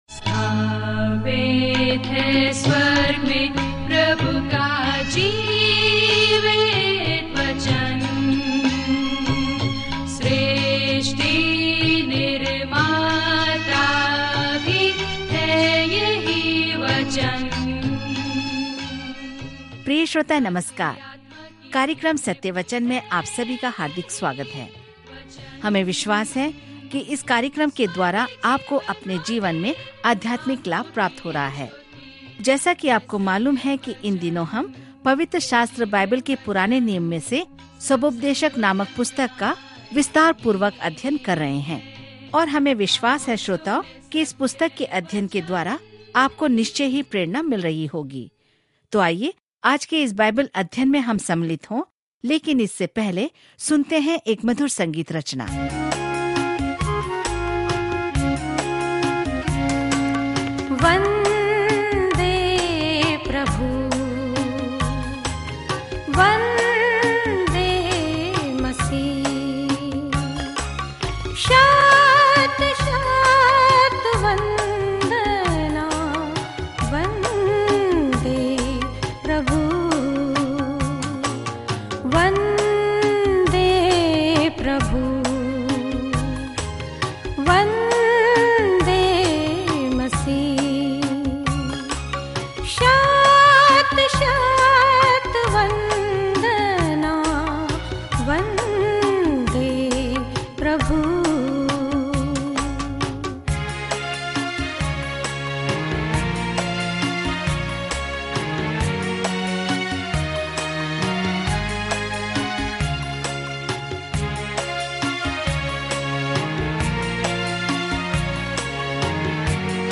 पवित्र शास्त्र सभोपदेशक 10:10-20 सभोपदेशक 11 सभोपदेशक 12:1 दिन 9 यह योजना प्रारंभ कीजिए दिन 11 इस योजना के बारें में एक्लेसिएस्टेस सुलैमान के जीवन की एक नाटकीय आत्मकथा है जब वह ईश्वर के बिना खुश रहने की कोशिश कर रहा था। एक्लेसिएस्टेस के माध्यम से दैनिक यात्रा पर आप ऑडियो अध्ययन सुनते हैं और भगवान के वचन से चुनिंदा छंद पढ़ते हैं।